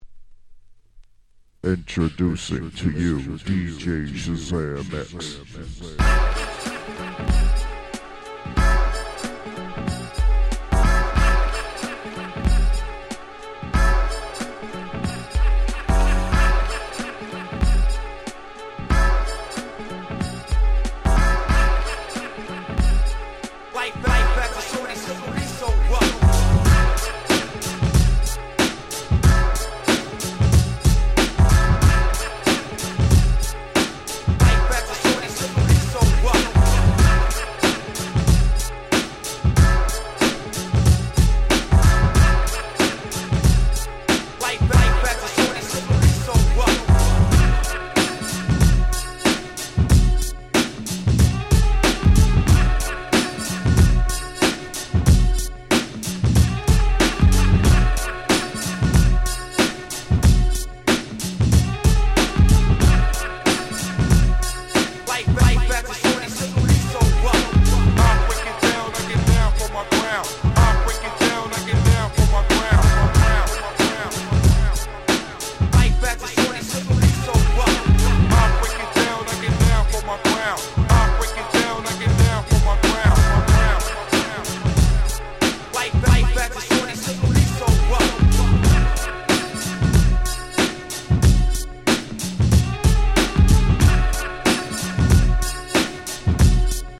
94' Underground Hip Hop Classics !!
90's Boom Bap ブーンバップ